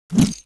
grenade_throw.wav